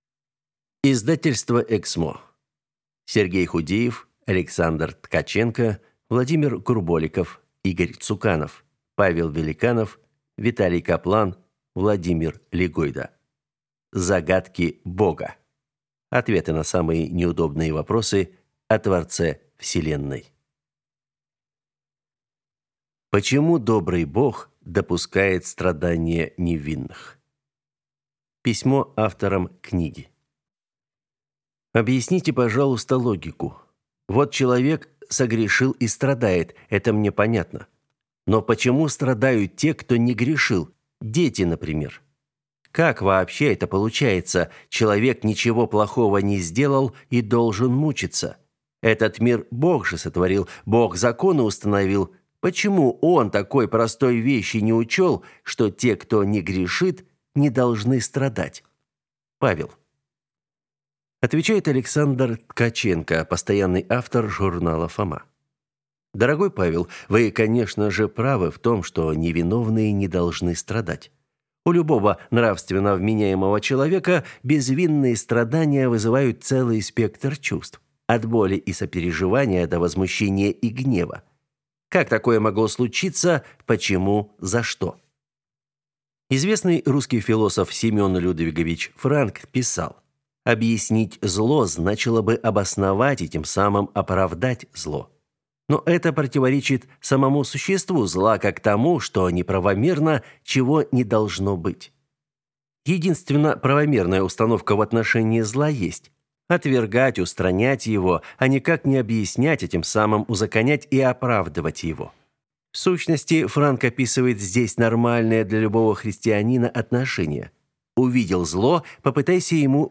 Аудиокнига Загадки Бога. Ответы на самые неудобные вопросы о Творце вселенной | Библиотека аудиокниг